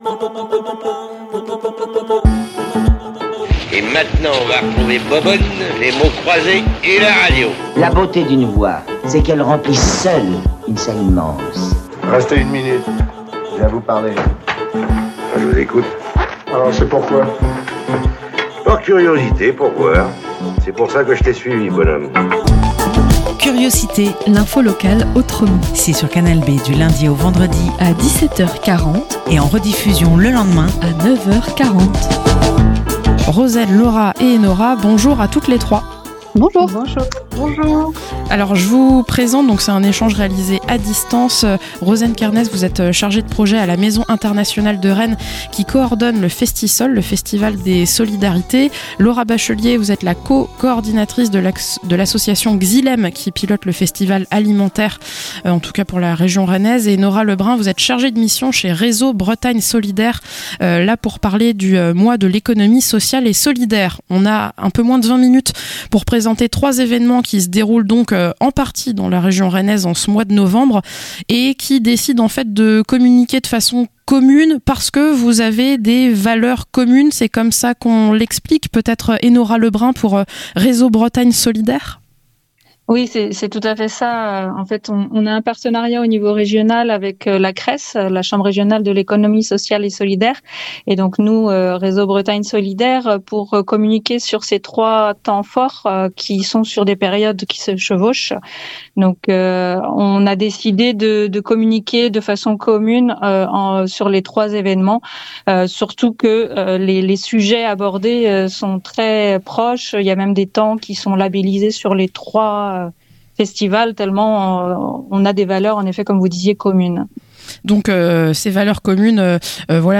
- Interview à trois voix pour annoncer le Festival des solidarités, Alimenterre et le Mois de l'Economie sociale et solidaire.